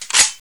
ak47m_slidebfw.wav